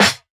jaydeelucyhardSnare.wav